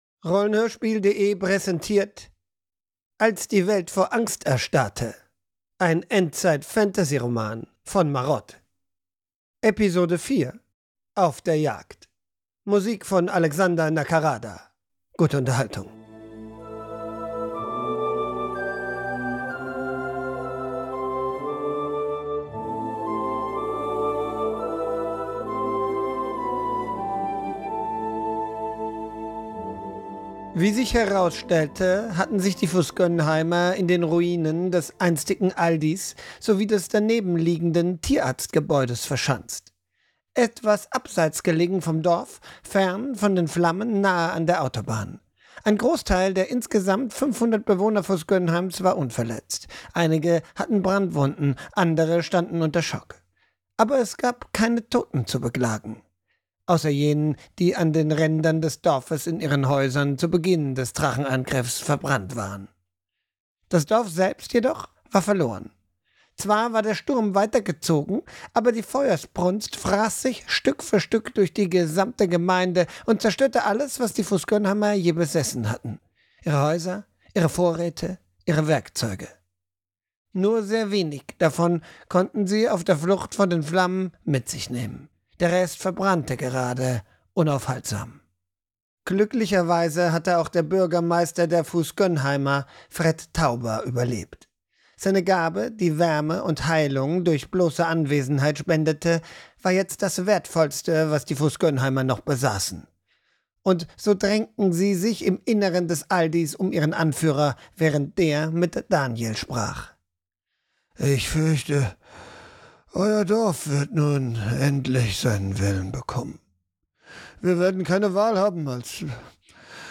(4) Als die Welt vor Angst erstarrte [Hörbuch] [Apokalypse] [Fantasy]